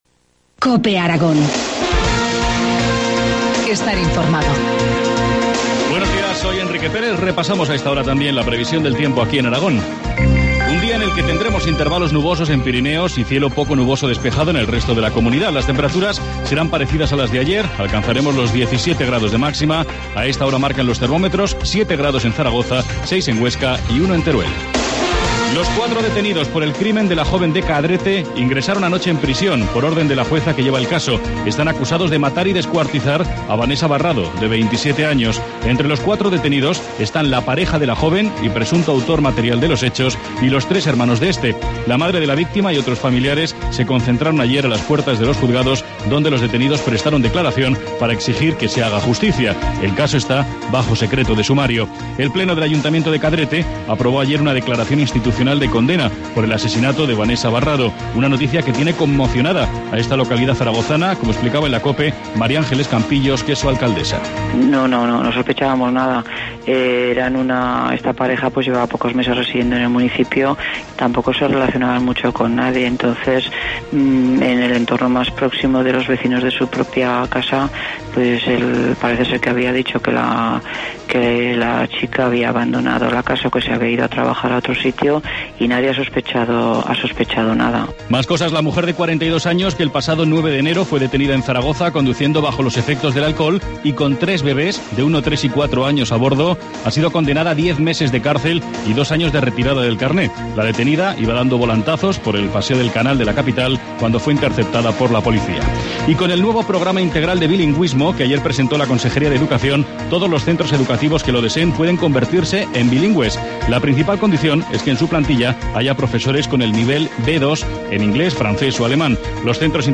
Informativo matinal, jueves 31 de enero, 8.25 horas